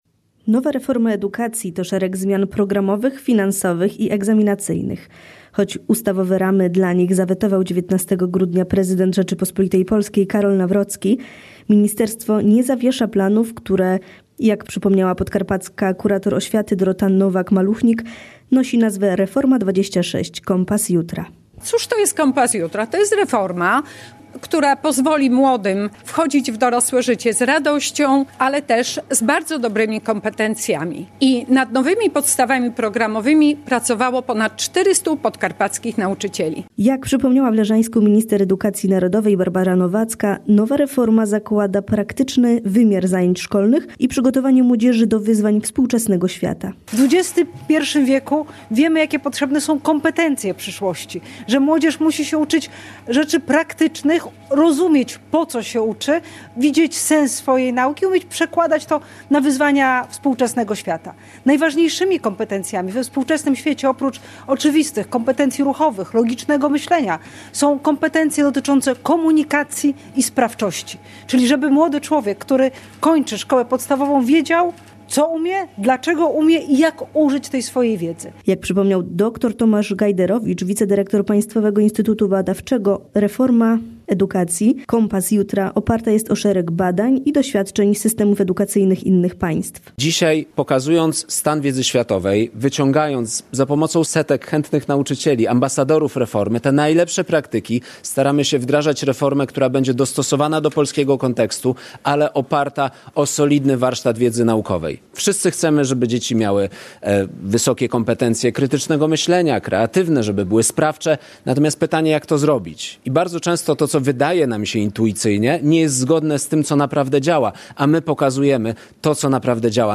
Relacje reporterskie • Leżajsk odwiedziła minister edukacji narodowej Barbara Nowacka. W Zespole Szkół Licealnych im. Bolesława Chrobrego spotkała się z nauczycielami i dyrektorami podkarpackich placówek, by rozmawiać o kolejnych etapach reformy edukacji.